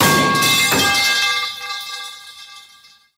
metalpipe.wav